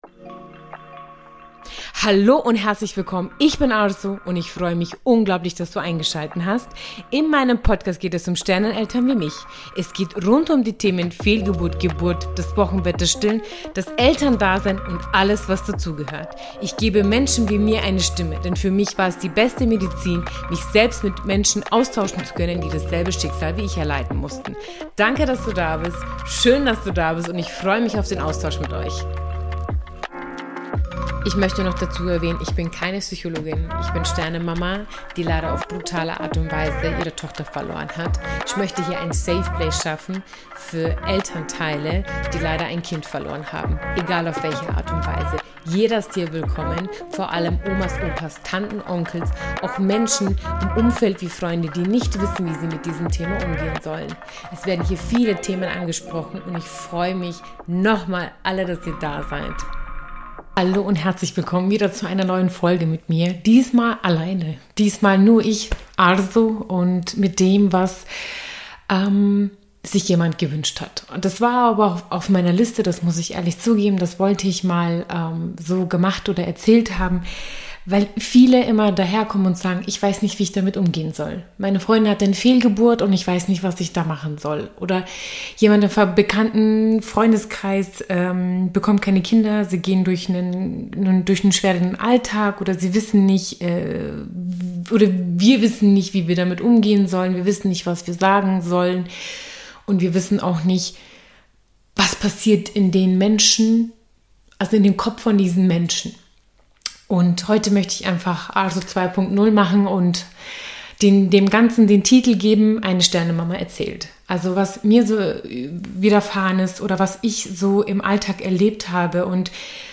Beschreibung vor 9 Monaten Eine Sternen Mama erzählt aus dem Alltag.